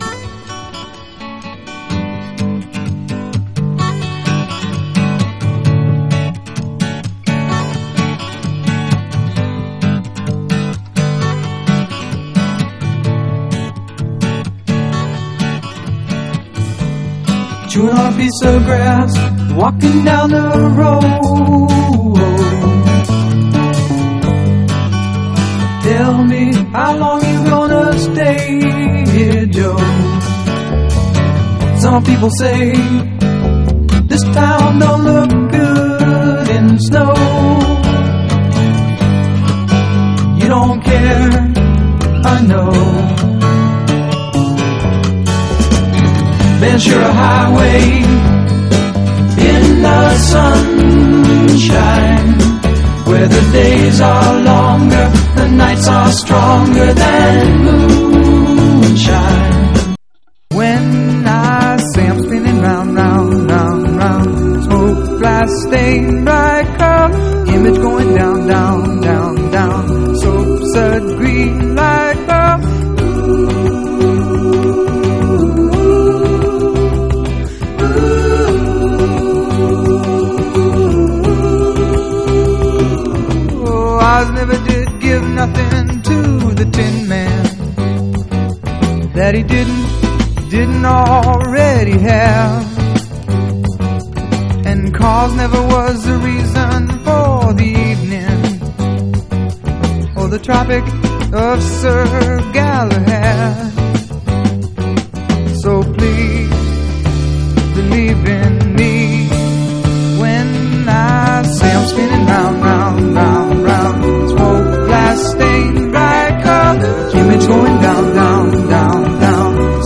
ROCK / PUNK / 70'S/POWER POP/MOD / WAVY POP
メロディーの良さと変なアレンジが相変わらずいい味出してます。